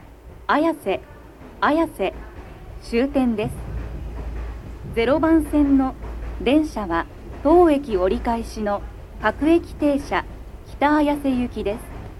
男声
到着放送1